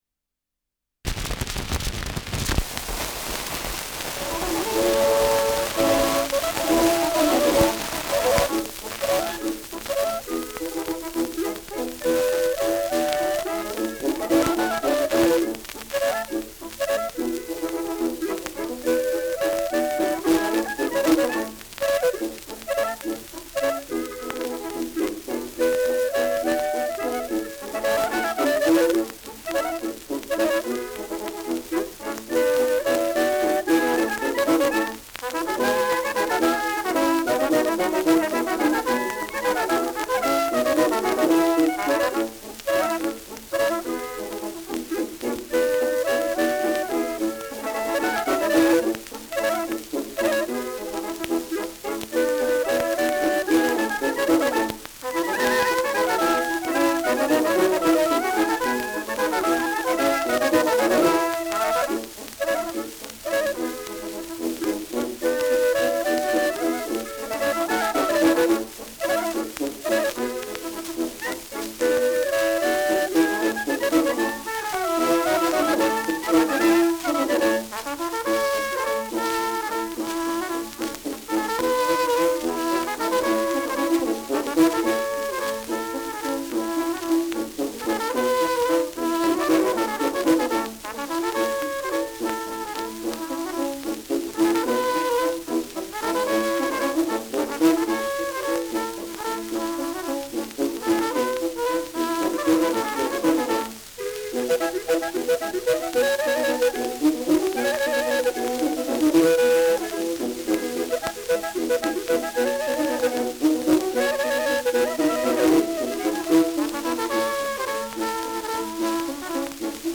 Schellackplatte
Zu Beginn starkes Grundrauschen : Gelegentlich stärkeres Knacken : Klirren an lauteren Stellen
Kapelle Pokorny, Bischofshofen (Interpretation)